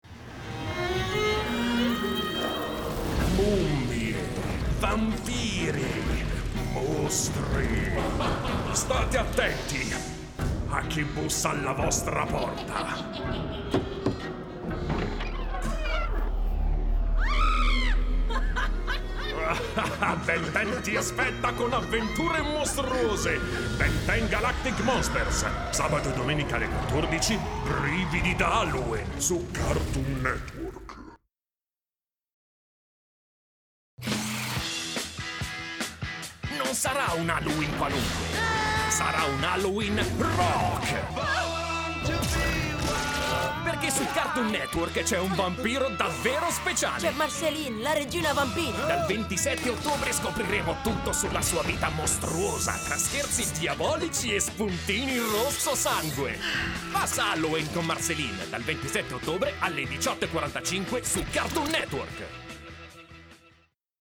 Voce baritonale e calda.
Sprechprobe: Sonstiges (Muttersprache):
Warm voice, precision, speed and efficacy.